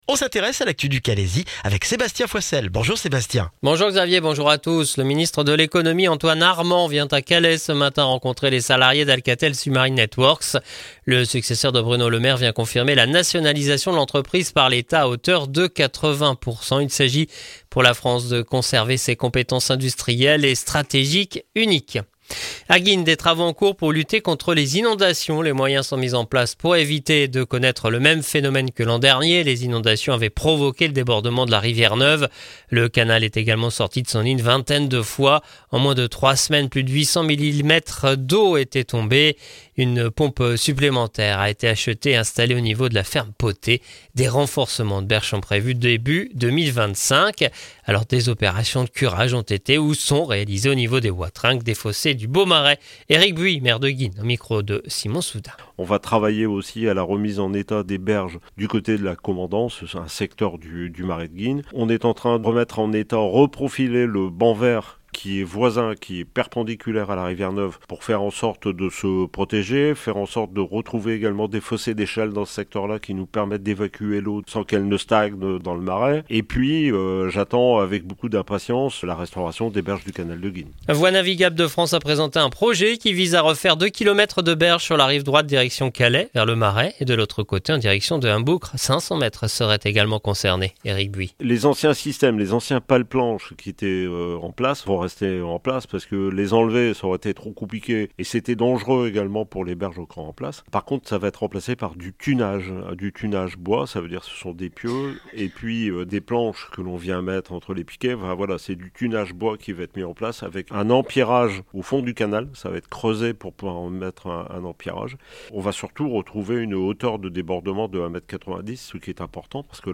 Le journal du mardi 5 novembre dans le Calaisis